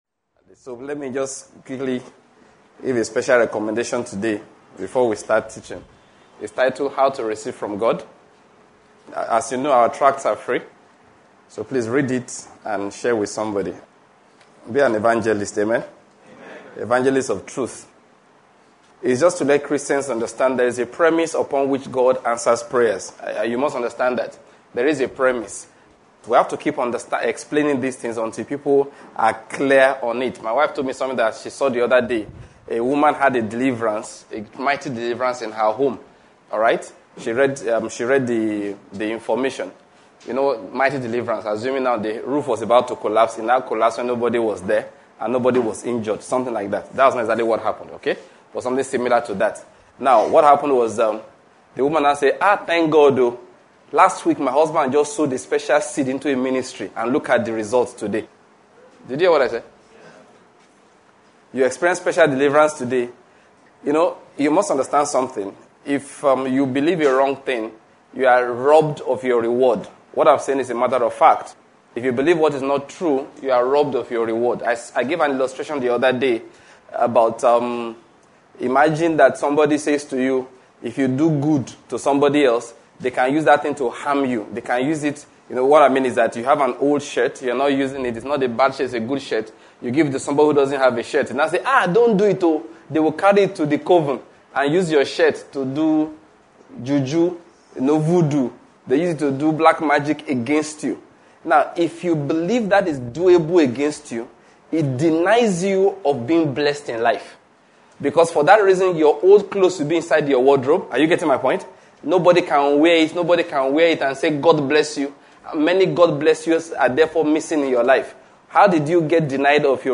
Kingdom-Word Ministries - THE SUPREMACY OF CHRIST AND OTHER SERMONS